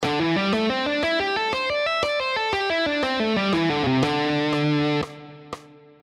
Here are the five positions of the A minor pentatonic scale, all played in triplets:
Triplet Lesson 3 – A Minor Pentatonic Position 3: